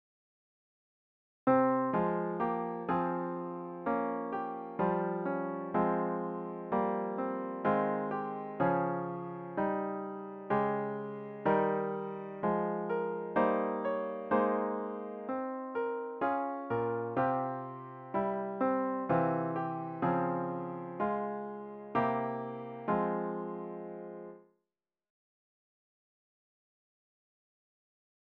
The hymn should be performed at a solemn♩= ca. 63.